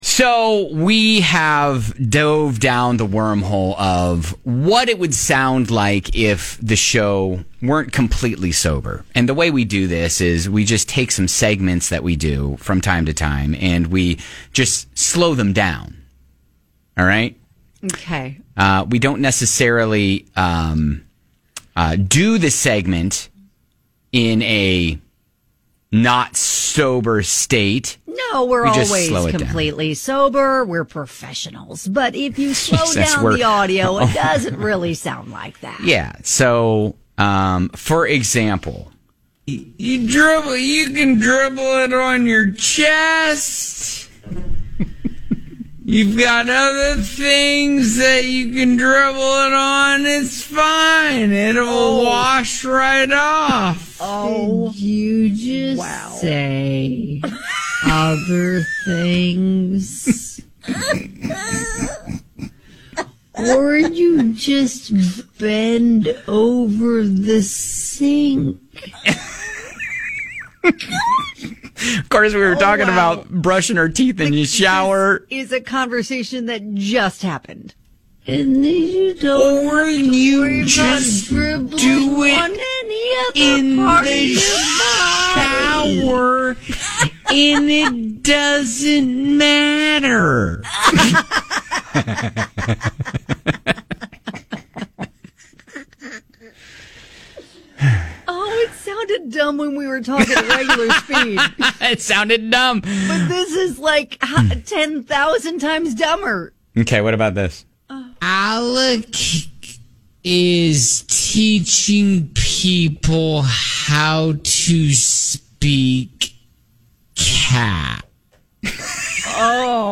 We take normal conversations on the show and slow them down. When we do, it makes it sound like we are on some kind of drug.